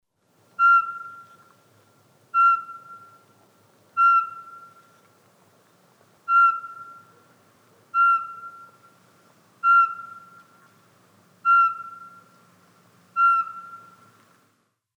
Chant-chouette-chevechette.mp3